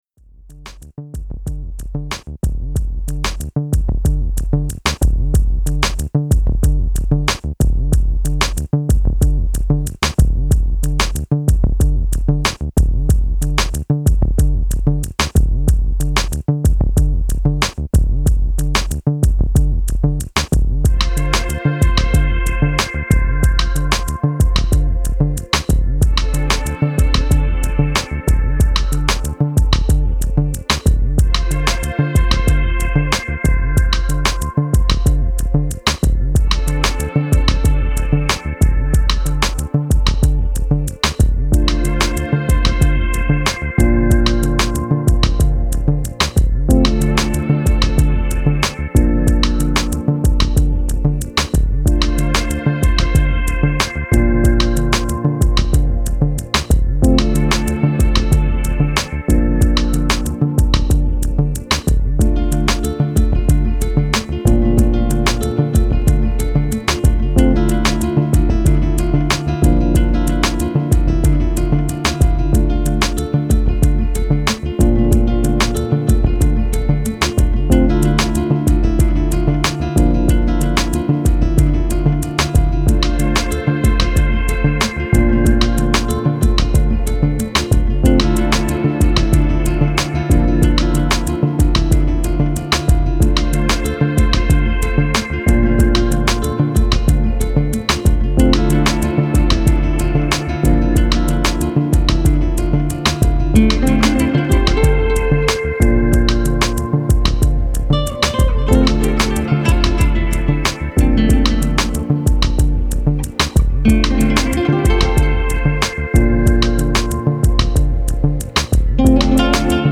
Genre: Chillout, Downtempo, Ambient.